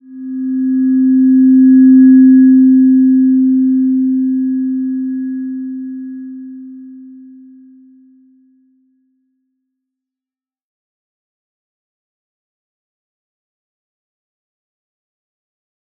Slow-Distant-Chime-C4-mf.wav